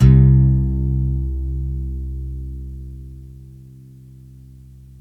GUITARRON01L.wav